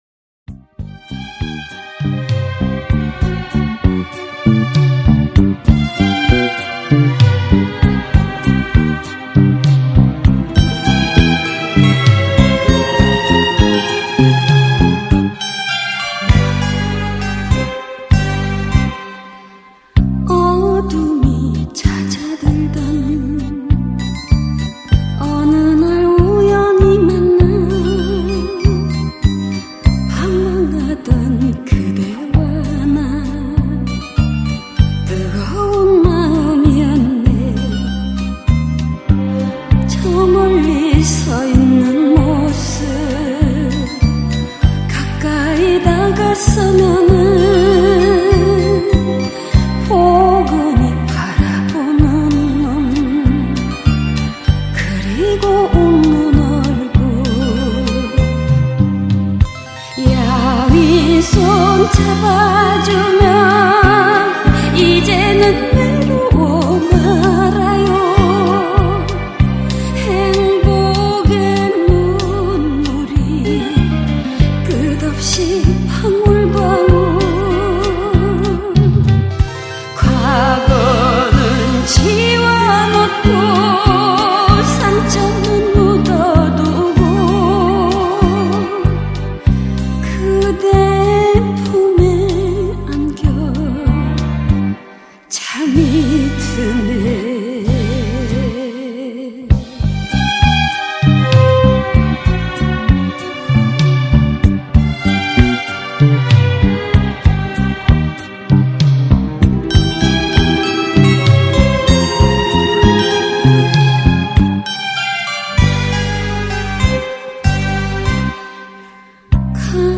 특히 A면의 리듬감은 일품이다.